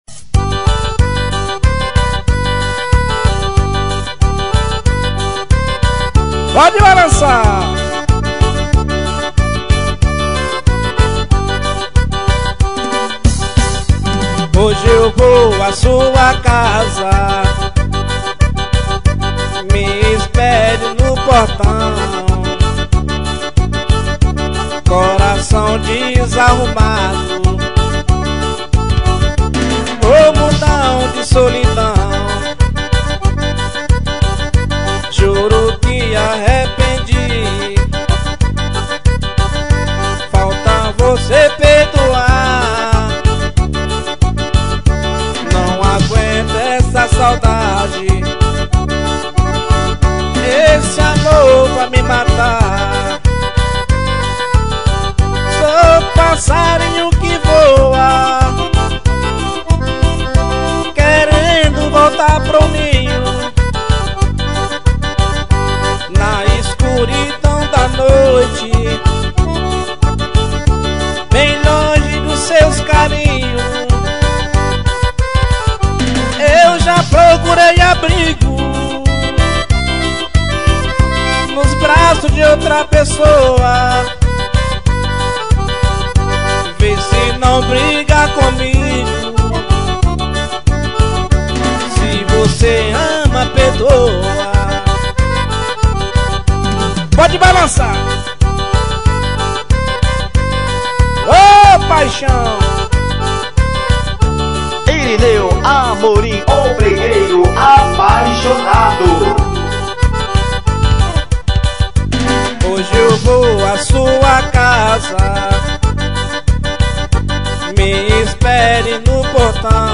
brega.